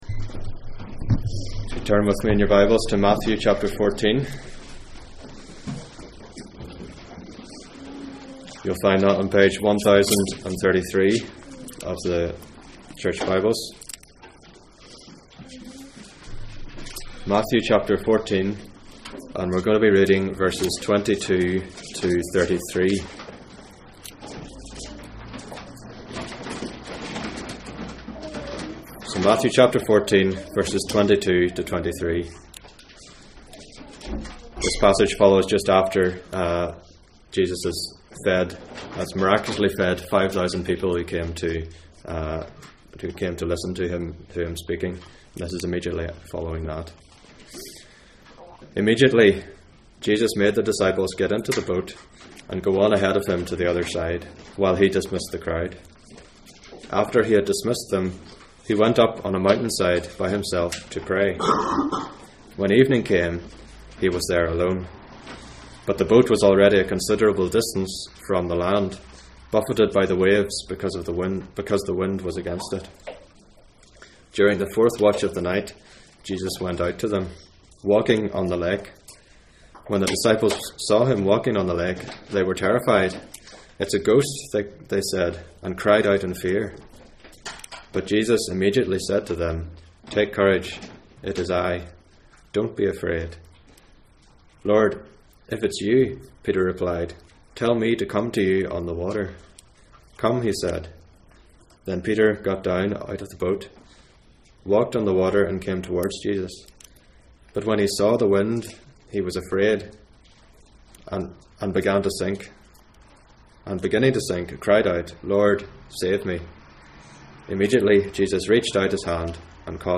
Matthew 8:23-27 Service Type: Sunday Morning %todo_render% « What is keeping you back from trusting in God?